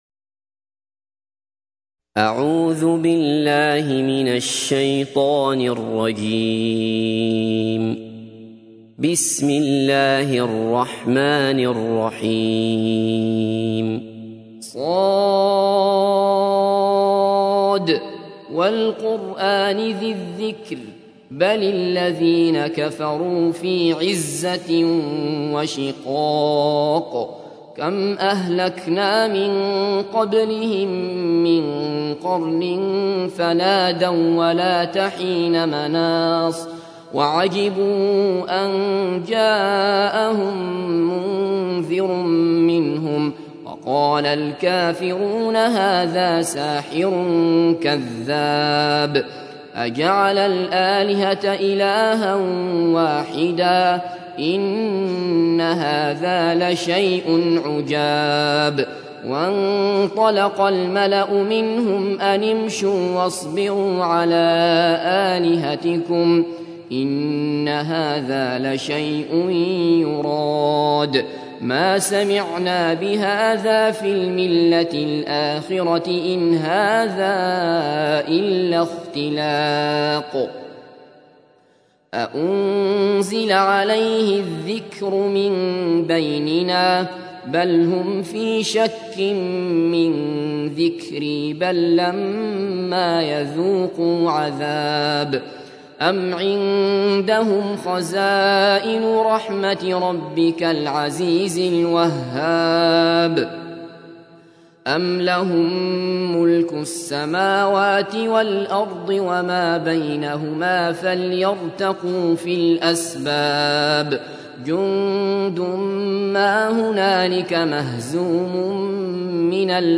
تحميل : 38. سورة ص / القارئ عبد الله بصفر / القرآن الكريم / موقع يا حسين